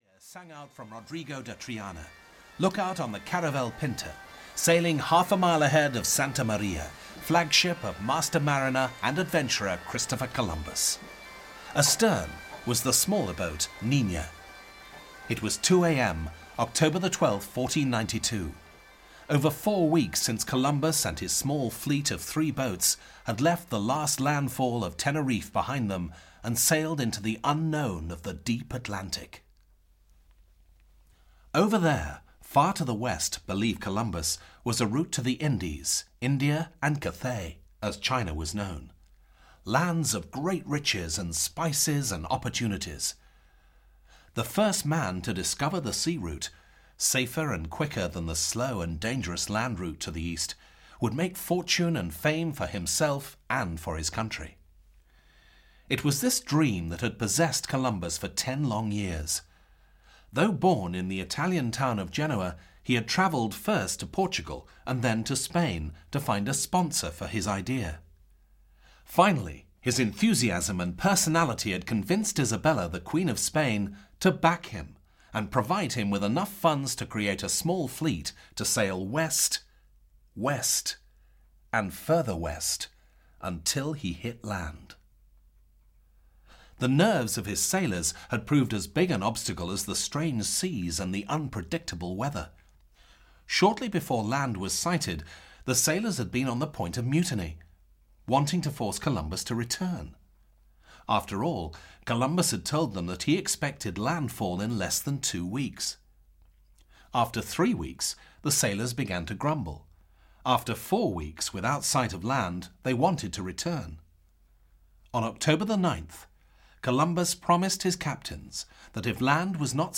Famous People in History – Volume 1 (EN) audiokniha
Ukázka z knihy